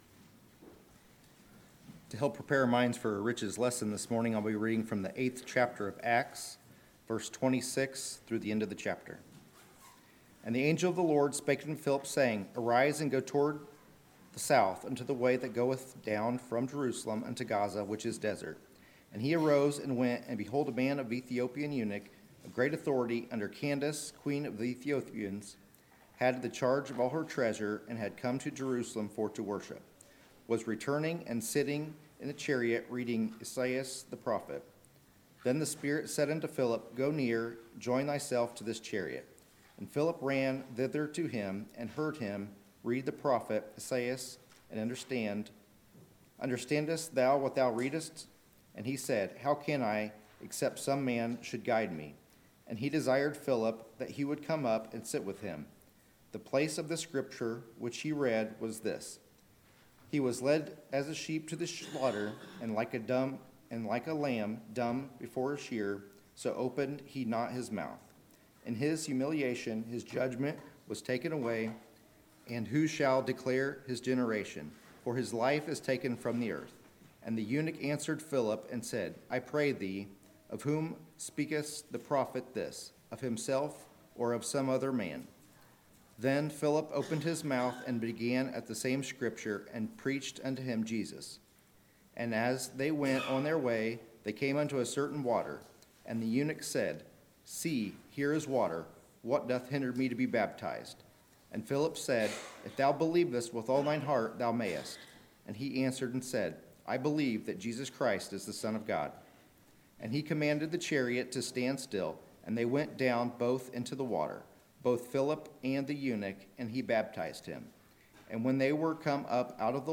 Sermons, December 2, 2018